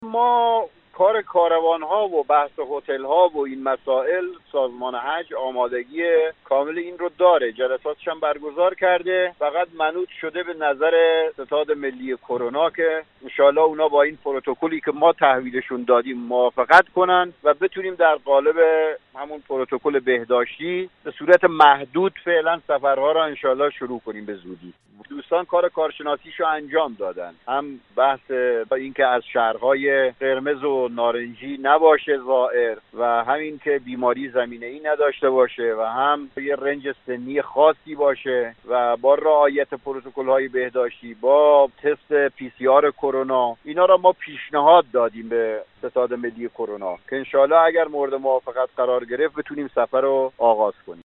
به گزارش خبر رادیو زیارت ، حجت الاسلام صحبت الله رحمانی در گفتگو با خبر رادیو زیارت درباره زمان نام نویسی زائران در دفاتر زیارتی و یا اعزام کاروان به عتبات عالیات گفت: